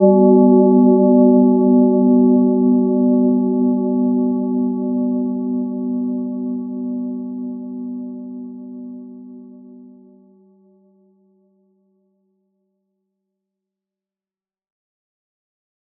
Gentle-Metallic-2-B3-mf.wav